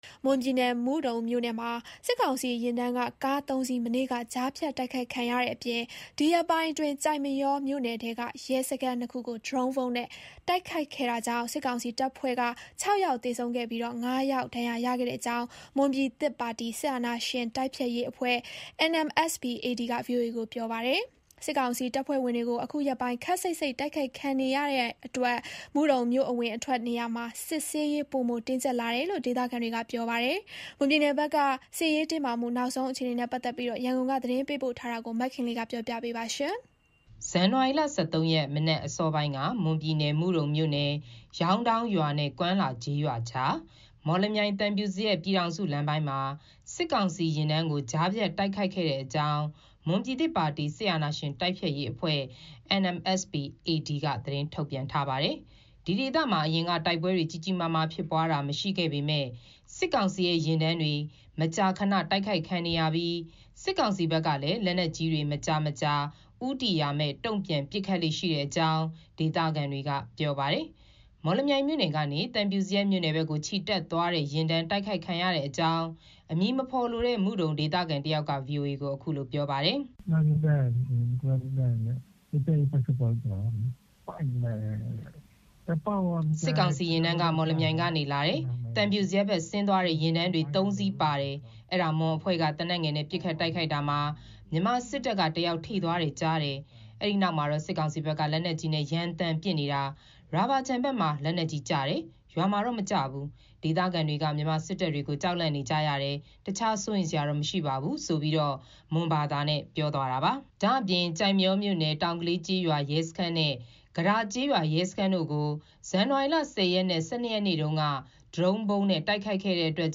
မွန်ပြည်နယ် မုဒုံမြို့နယ်မှာ စစ်ကောင်စီယာဉ်တန်းက ကားသုံးစီး မနေ့က ကြားဖြတ်တိုက်ခိုက်ခံရတဲ့ အပြင် ဒီရက်ပိုင်းအတွင်း ကျိုက်မရောမြို့နယ်ထဲက ရဲစခန်းနှစ်ခုကို ဒရုန်းဗုံးနဲ့တိုက်ခိုက်ခဲ့တာကြောင့် စစ်ကောင်စီ တပ်ဖွဲ့က (၆) ယောက်သေဆုံးပြီး (၅) ယောက်ဒဏ်ရာရခဲ့ကြောင်း မွန်ပြည်သစ်ပါတီ စစ်အာဏာရှင်တိုက်ဖျက်ရေးအဖွဲ့ NMSP-AD က ဗွီအိုအေကိုပြောပါတယ်။ စစ်ကောင်စီတပ်ဖွဲ့ဝင် တွေကို အခုရက်ပိုင်း ခပ်စိတ်စိတ် တိုက်ခိုက်ခံနေရတဲ့အတွက် မုဒုံ မြို့အဝင်အထွက်နေရာမှာ စစ်ဆေးရေး ပိုမိုတင်းကြပ်လာတယ်လို့ ဒေသခံတွေ ပြောပါတယ်။ မွန်ပြည်နယ်က စစ်ရေးတင်းမာမှု နောက်ဆုံးအခြေအနေ ရန်ကုန်က သတင်းပေးပို့ထားပါတယ်။